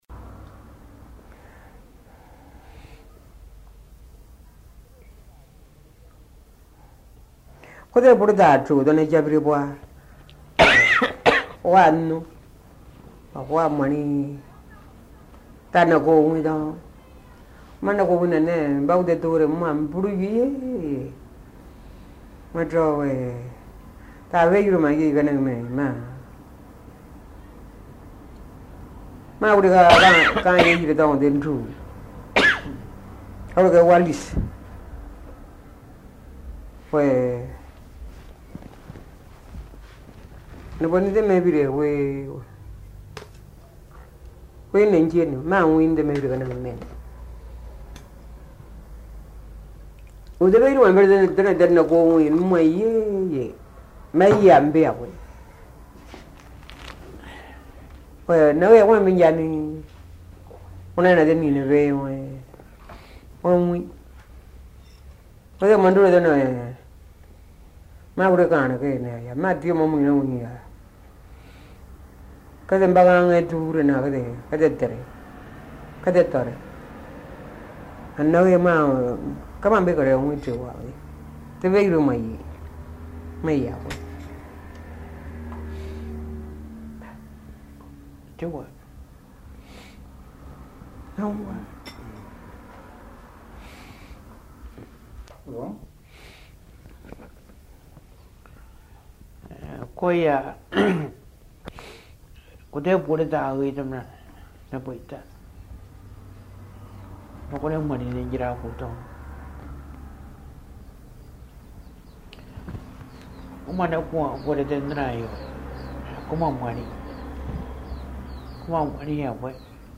Documents joints Dialogue leçon 25 ( MP3 - 3.4 Mio ) Un message, un commentaire ?